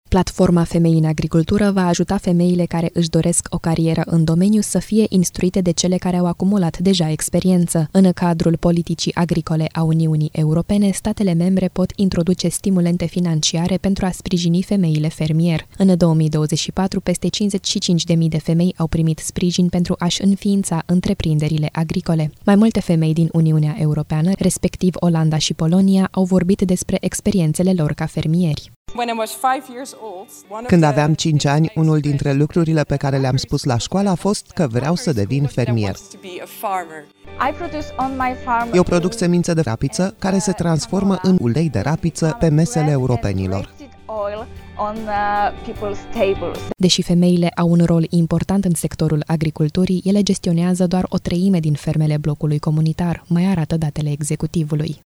Mai multe femei din Uniunea Europeană, respectiv din Olanda și Polonia, au vorbit despre experiențele lor ca fermieri: